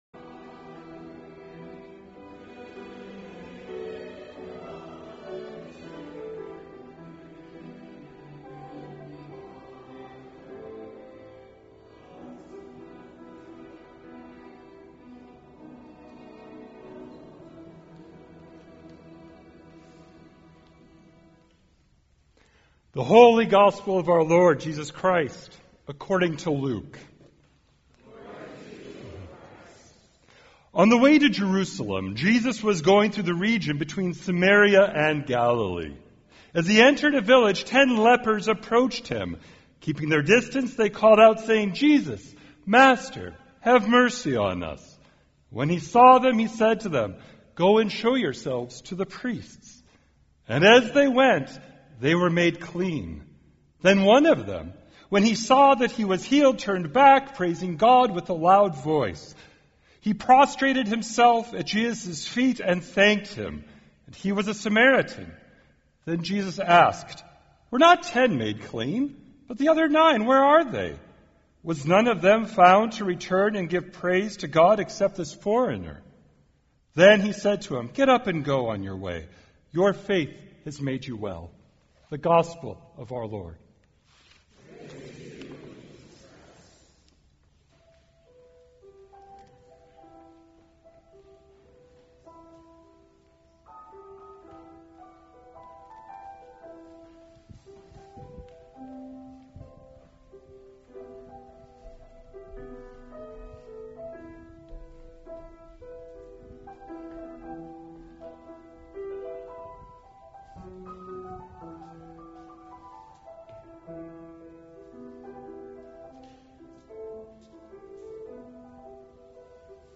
Recent Sermons - Trinity Episcopal Cathedral